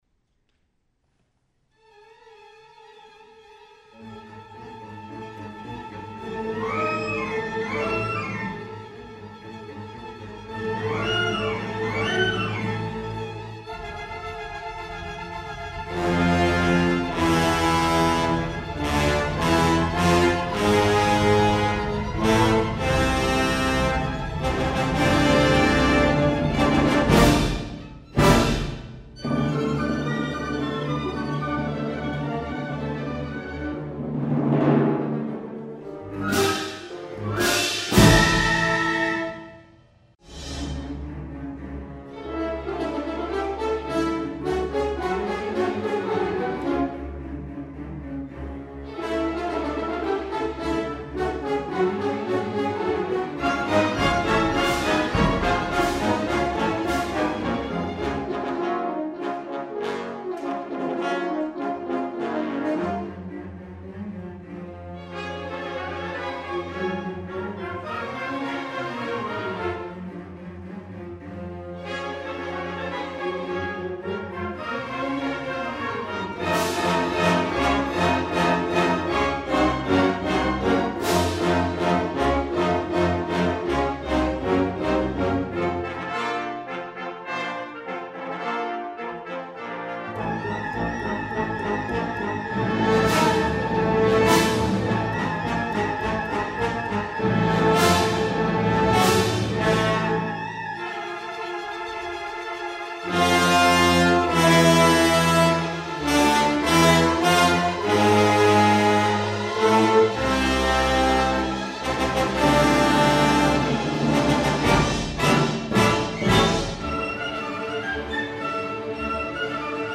"Night on the Bald Mountain" usually refers to one of two compositions – either a seldom performed early (1867) 'musical picture' by Modest Mussorgsky, St. John's Night on the Bare Mountain, or a later (1886) and very popular 'fantasy for orchestra' by Nikolay Rimsky-Korsakov, A Night on the Bare Mountain, based almost entirely on Mussorgsky's themes.